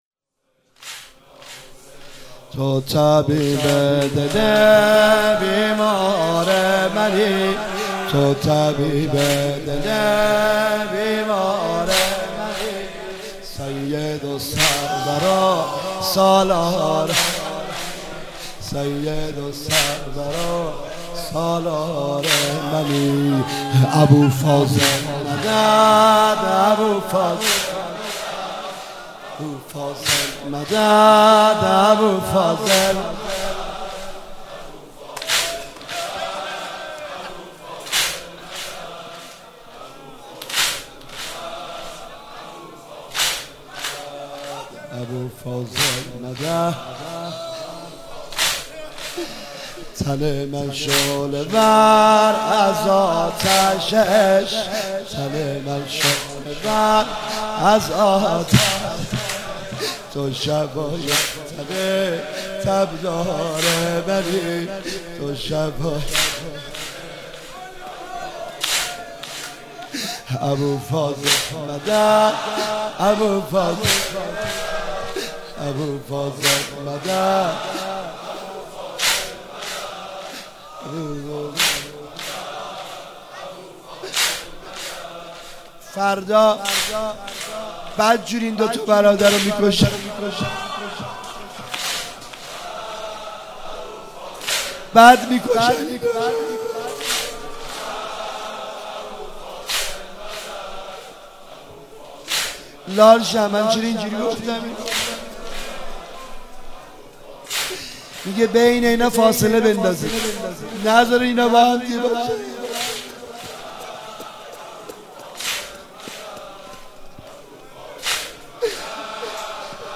شب عاشورای محرم 96
زمینه(وای بر حالم)
زمزمه(خودم خبر دارم....)
واحد(آسمان در عزا با ماه اخترش....)
شور(وا عطشا به کربلا...)